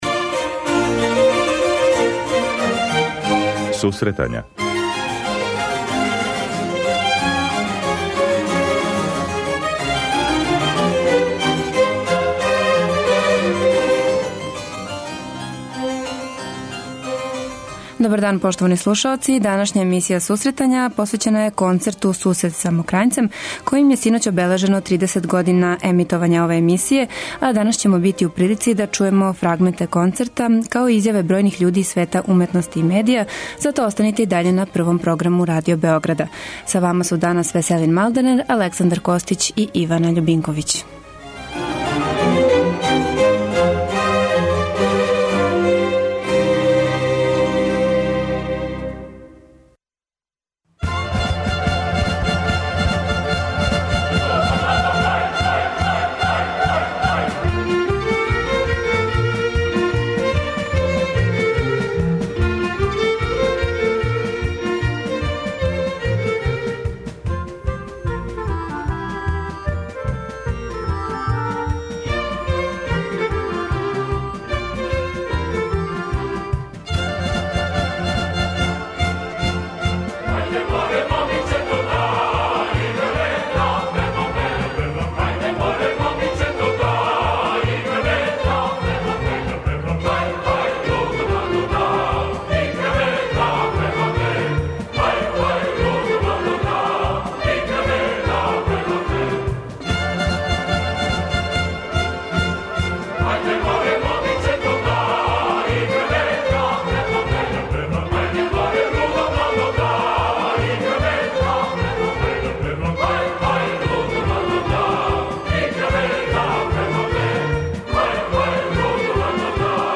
Пренећемо део атмосфере са гала концерта "Сусрет са Мокрањцем", којим је обележено 30 година емитовања емисије Сусретања на програму Радио Београда 1. Слушаћемо снимак са концерта и чути мишљења еминентних личности из српског културног живота о значају емисије у афирмацији уметничке музике.